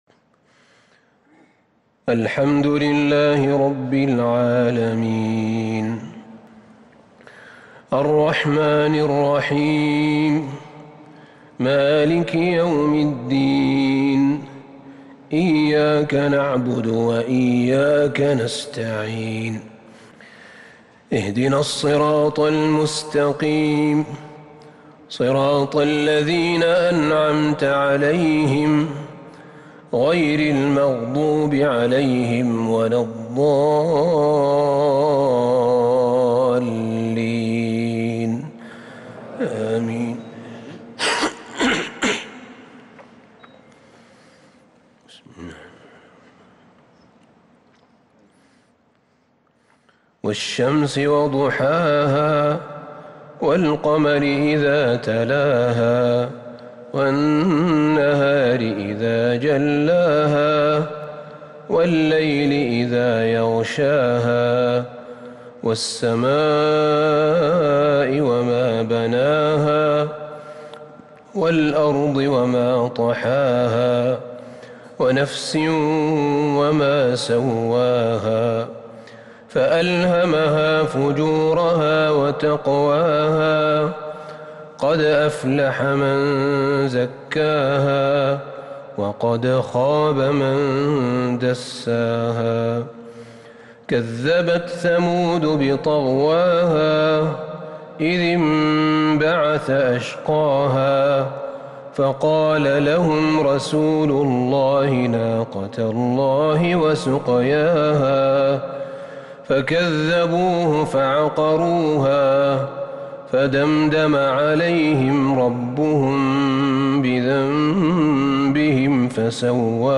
صلاة العشاء للشيخ أحمد بن طالب حميد 9 ذو الحجة 1442 هـ
تِلَاوَات الْحَرَمَيْن .